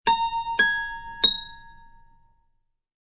Descarga de Sonidos mp3 Gratis: guitarra b.